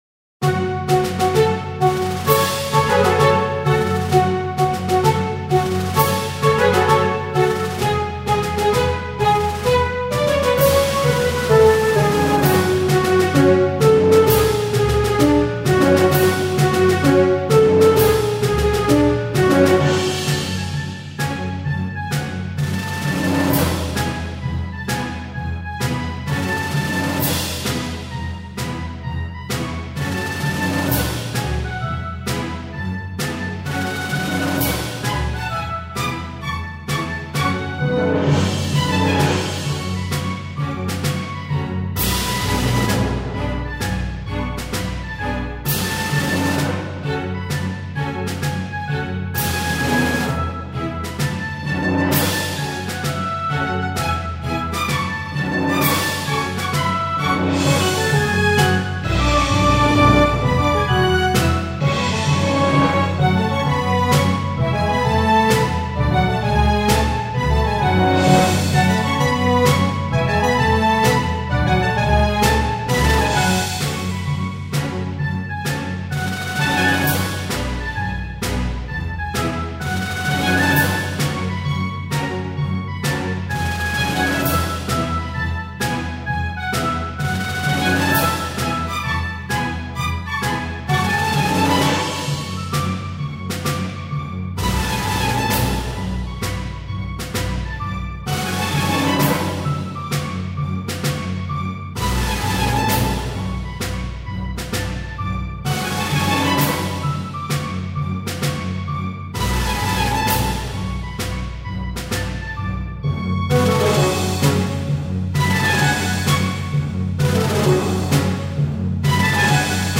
بی‌کلام